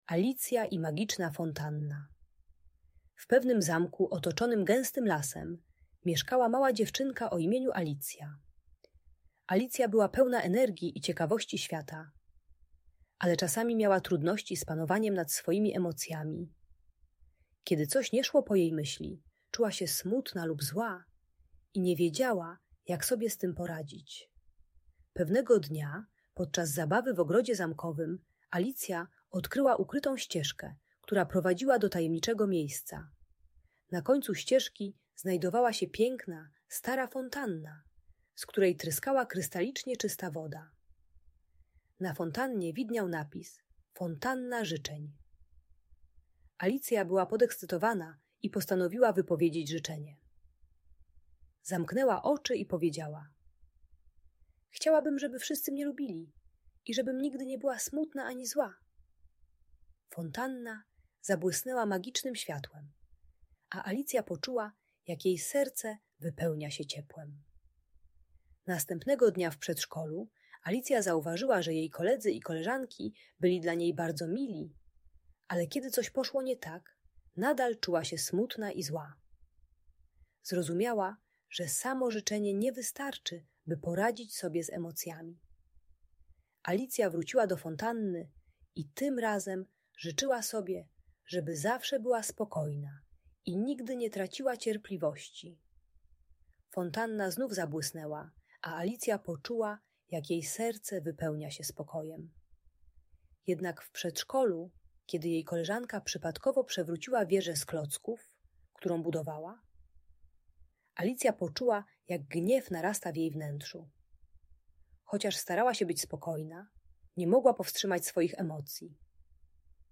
Alicja i Magiczna Fontanna - Audiobajka